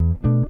gtr_07.wav